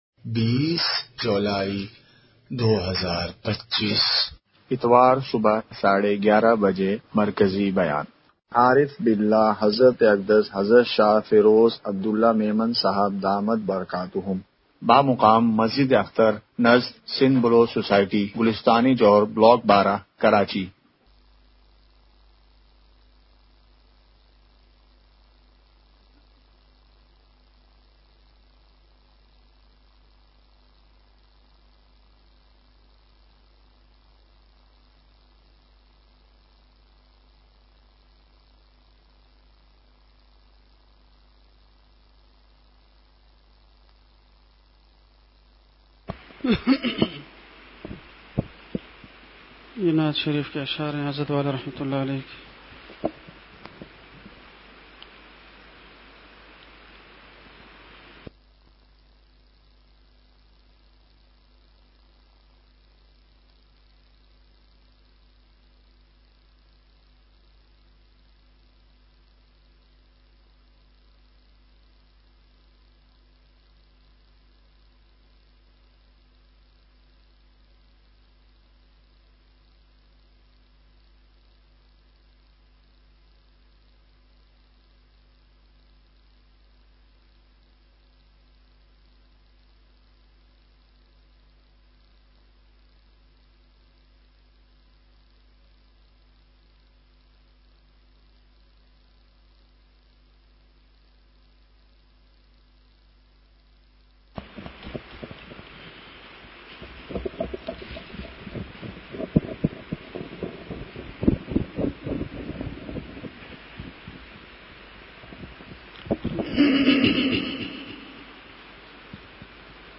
*مقام:مسجد اختر نزد سندھ بلوچ سوسائٹی گلستانِ جوہر کراچی*
*15:13) بیان سے پہلے اشعار ہوئے۔۔*